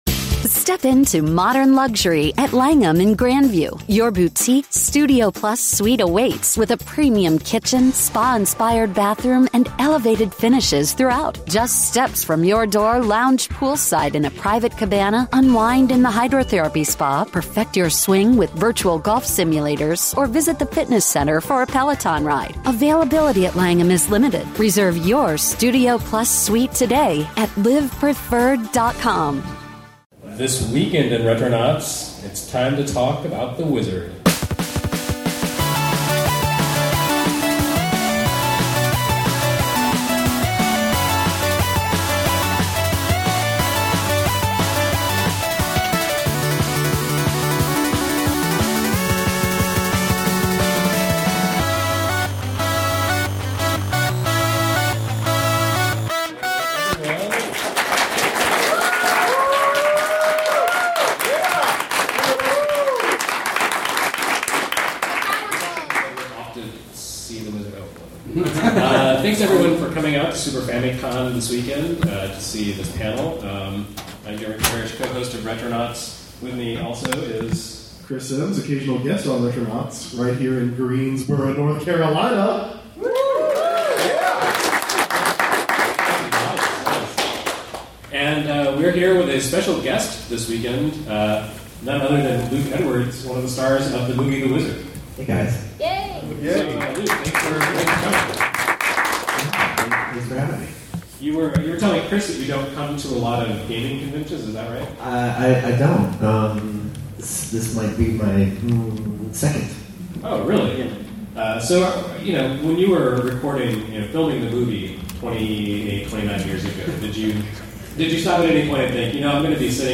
Live from Super FamiCon